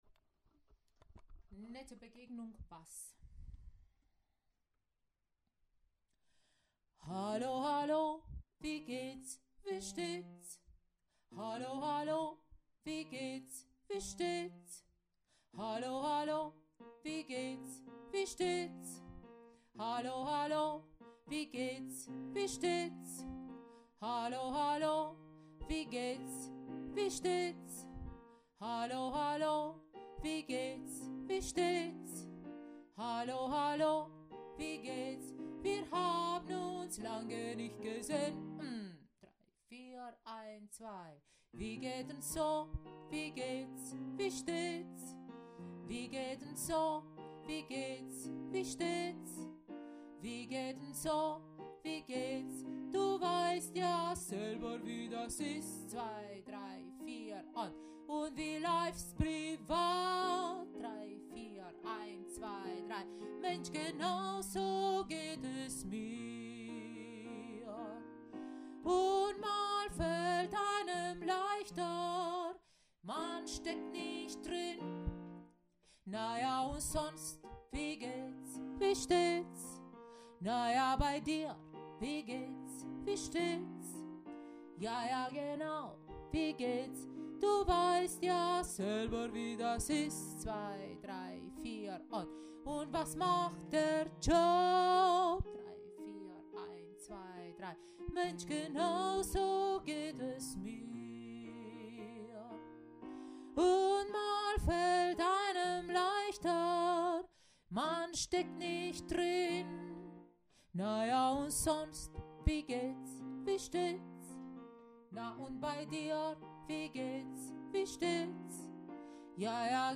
Nette Begegnung Bass